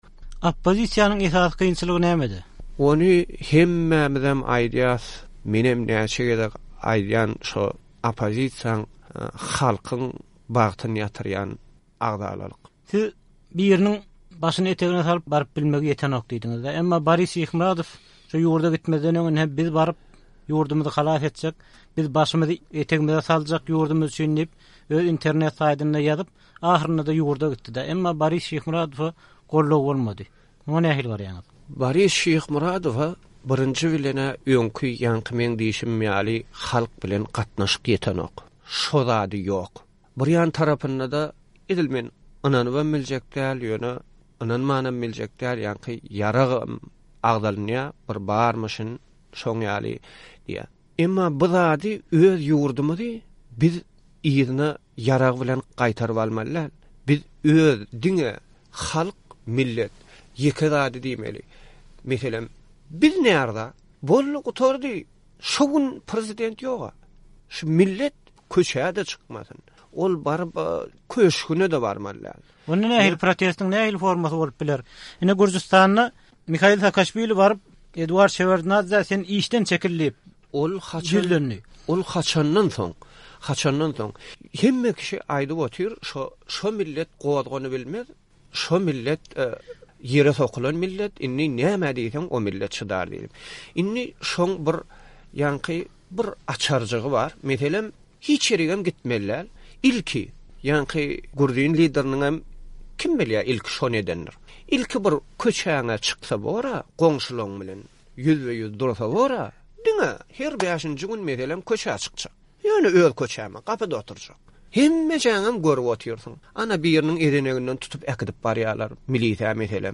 2004-nji ýylda geçirilen söhbetdeşlikden bir bölegi diňleýjilerine ýetirmegi makul bildi.